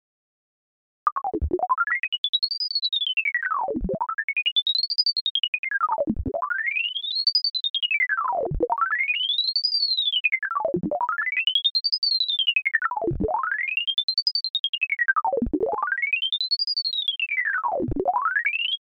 そして、ピッチの変化のスピードを決めているパラメータのうち、 Rangs of Offset Distributionの値を10から50にしたものです。 ピッチの変化が速くなっています。